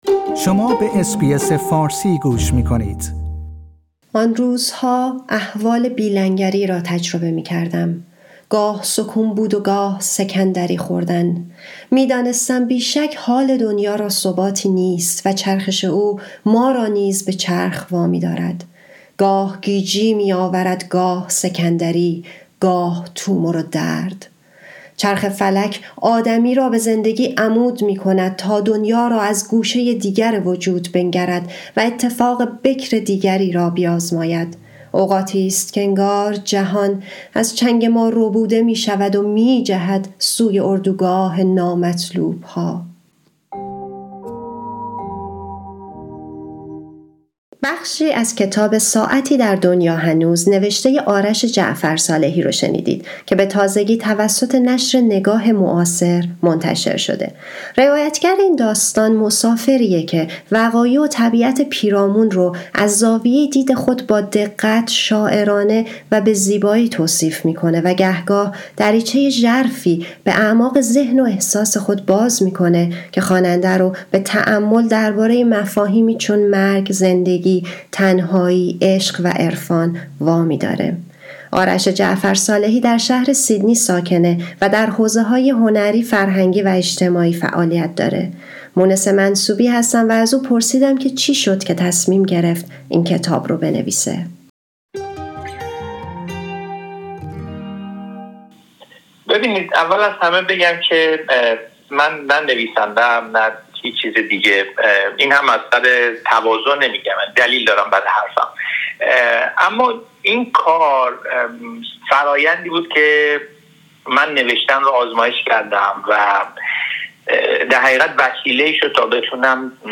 گپ و گفتگویی